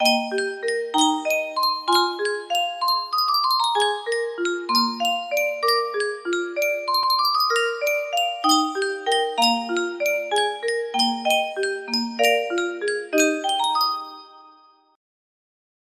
Yunsheng Music Box - The Skaters' Waltz 049Y music box melody
Full range 60